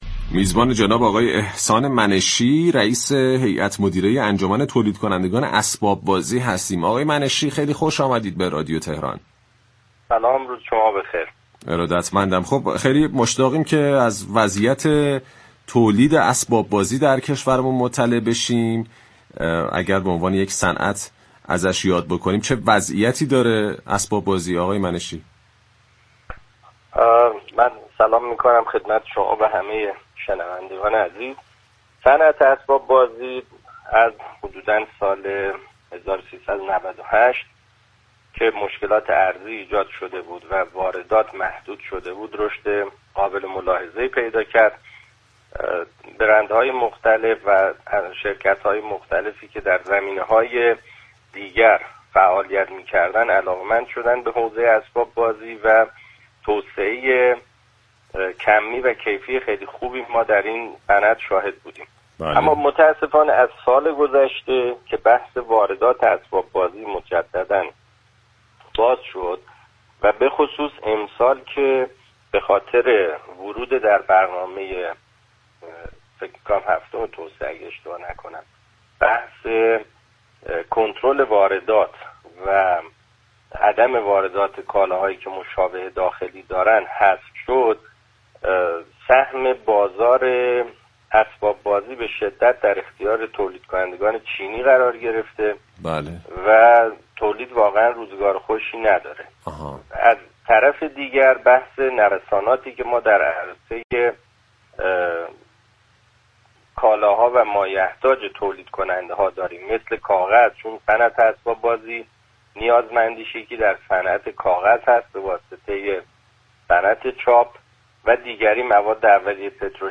در گفت و گو با «بازار تهران»